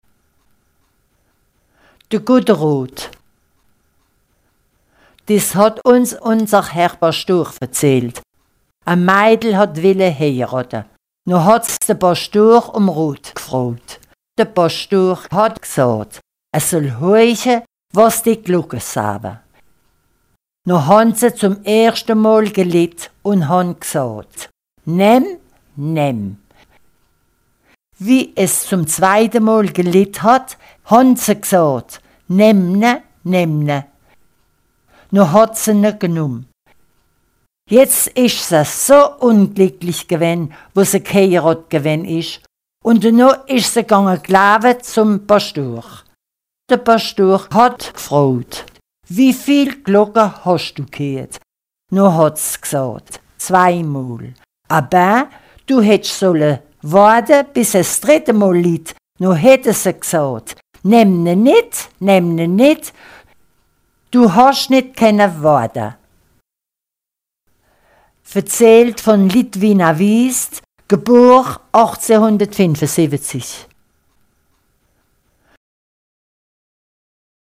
Contes et récits enregistrés dans les communes de Walscheid, Troisfontaines, Hartzviller, Plaine de Walsch, Niderviller, Schneckenbusch, Sarrebourg, Réding et Langatte.
Réding